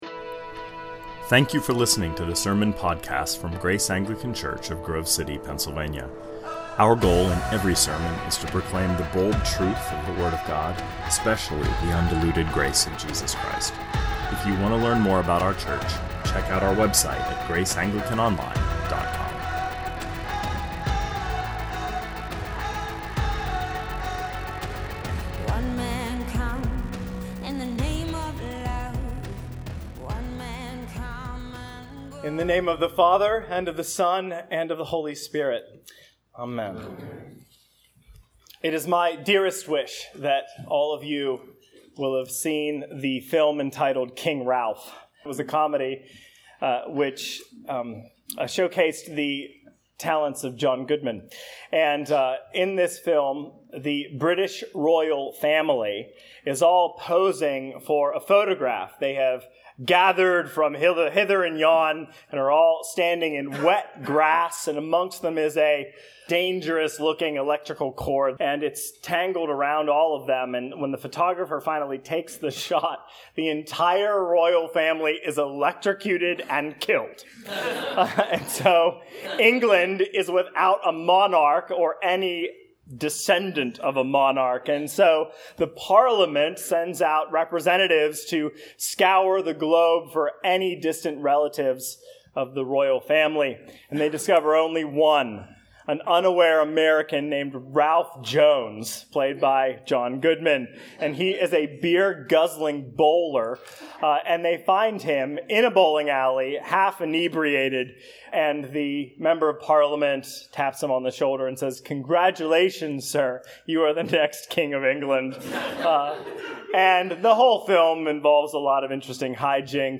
2019 Sermons You've Got a Prayer in Memphis -Colossians 3 Play Episode Pause Episode Mute/Unmute Episode Rewind 10 Seconds 1x Fast Forward 30 seconds 00:00 / 30:27 Subscribe Share RSS Feed Share Link Embed